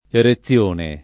[ ere ZZL1 ne ]